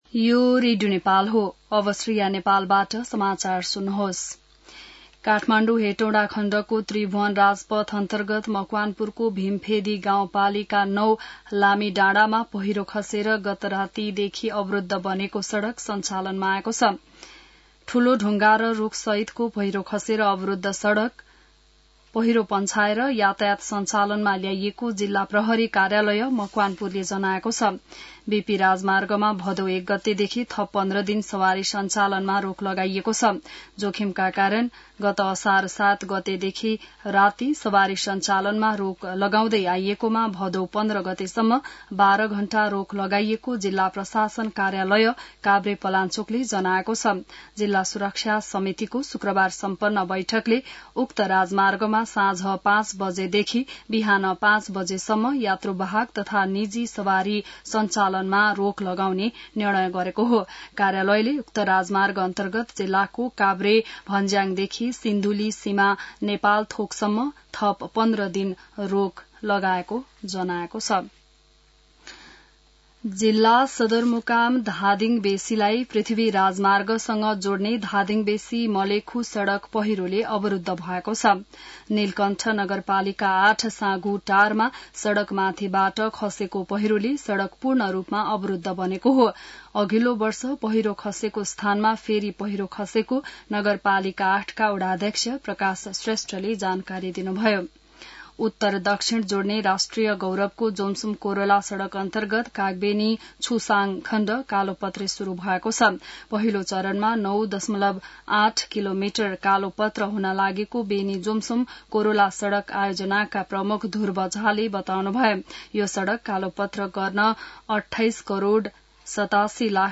बिहान ६ बजेको नेपाली समाचार : १ भदौ , २०८२